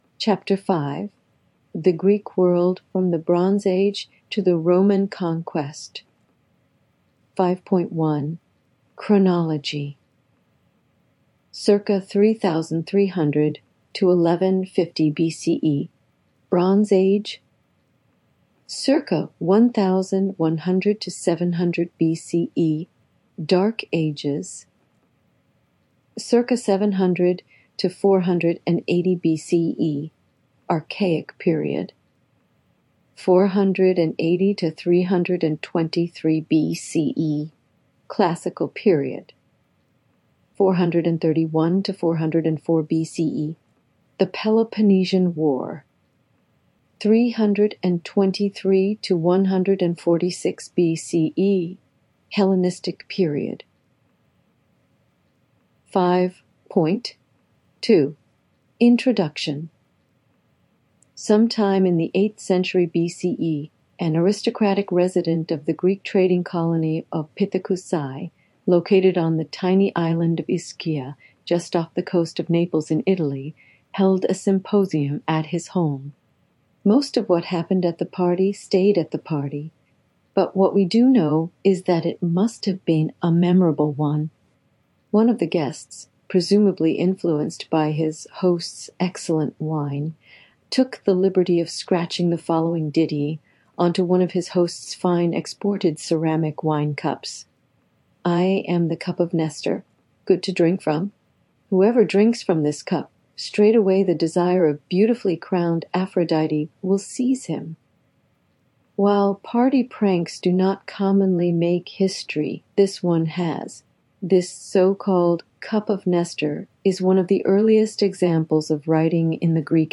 World History Audiobook - Chapter 5